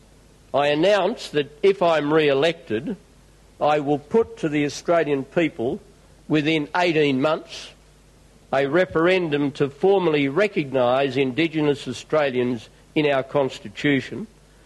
This audio was first broadcast on ABC Radio National PM Report 11 October 2007.